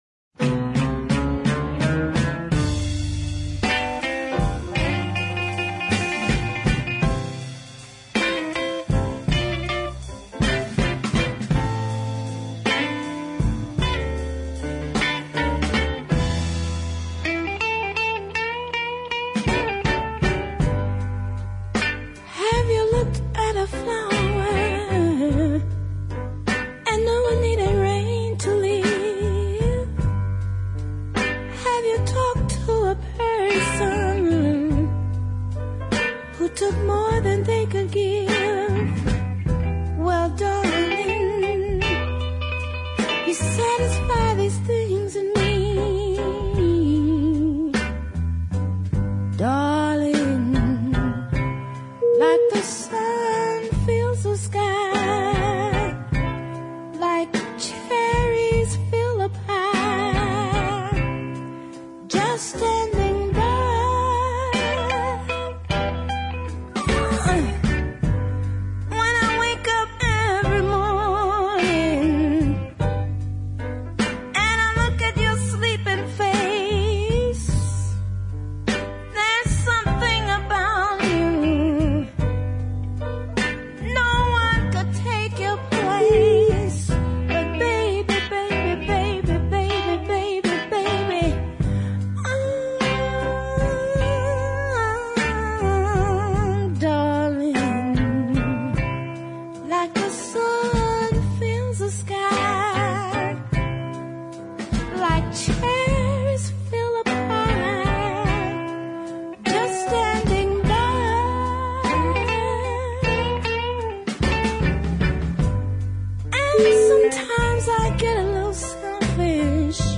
being more like demos or rough tracks